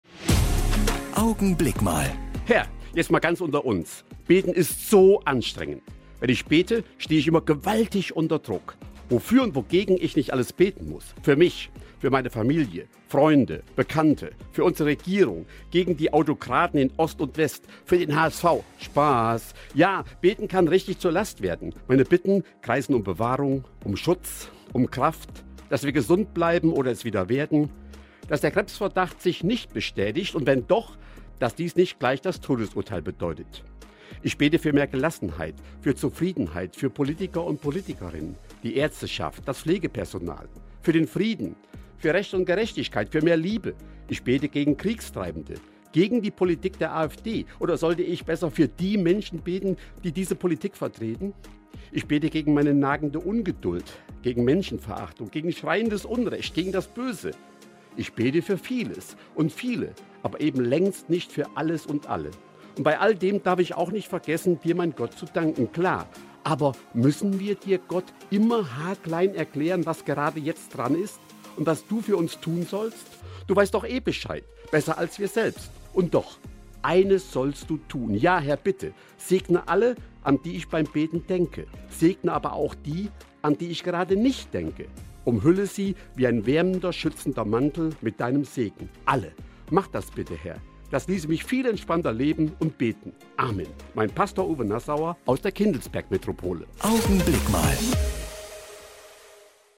Augenblick Mal - die Kurzandacht im Radio